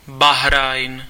Ääntäminen
Vaihtoehtoiset kirjoitusmuodot Bahrein Ääntäminen US Tuntematon aksentti: IPA : /bɑːˈɹeɪn/ Lyhenteet ja supistumat (laki) Bahr.